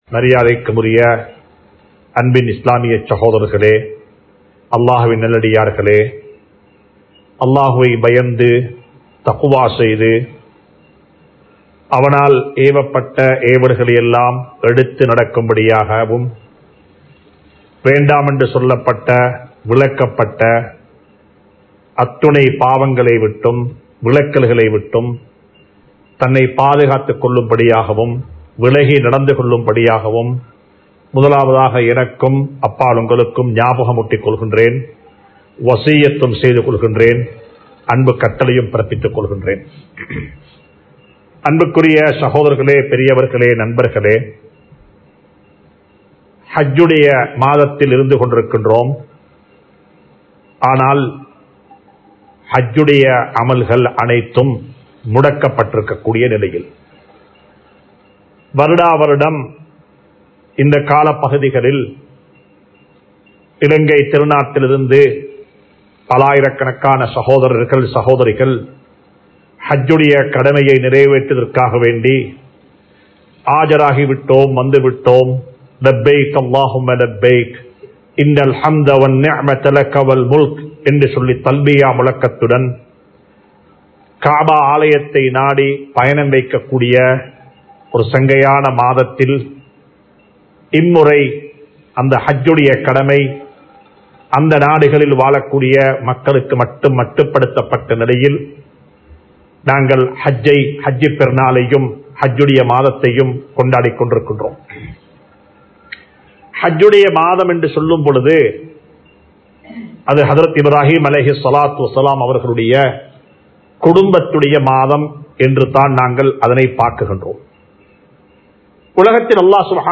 இப்றாஹீம்(அலை)அவர்கள் கேட்ட துஆ (Prophet Ibraheem's (alai) Supplication) | Audio Bayans | All Ceylon Muslim Youth Community | Addalaichenai
Al Hudha Jumua Masjidh